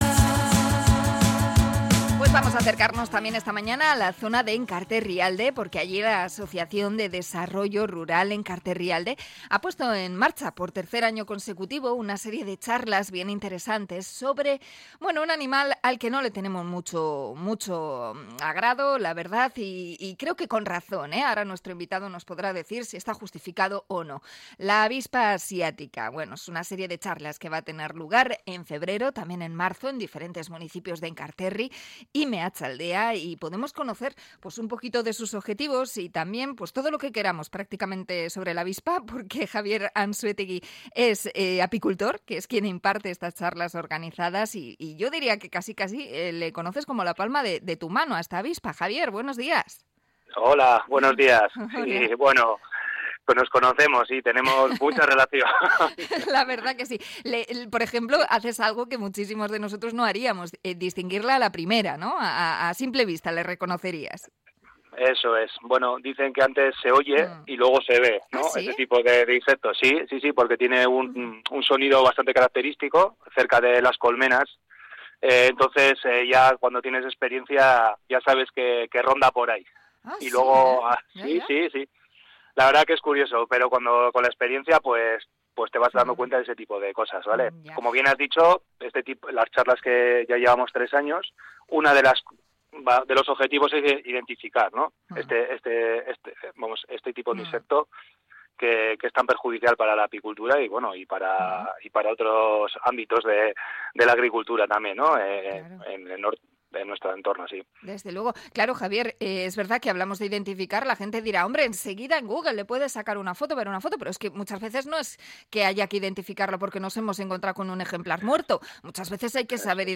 Entrevista a apicultor sobre las charlas en enkarterrialde sobre la vespa velutina